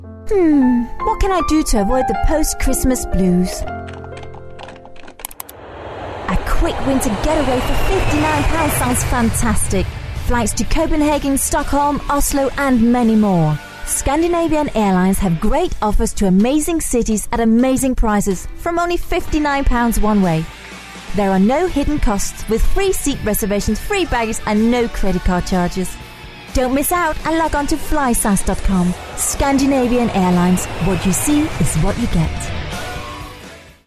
Danish, Scandinavian, Female, 20s-30s.